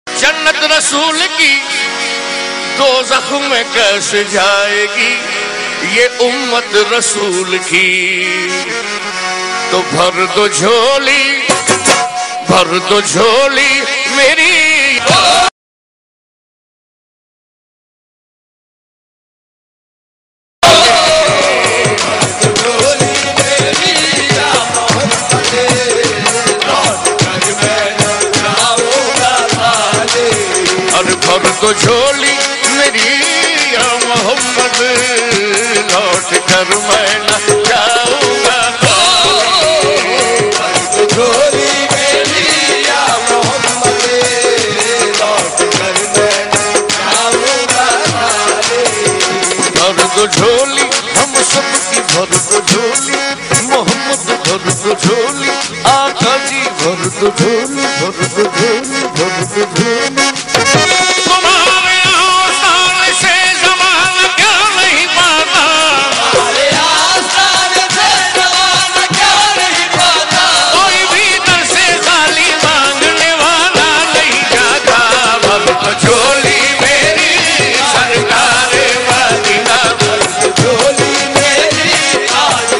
qawwali